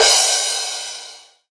DJP_PERC_ (8).wav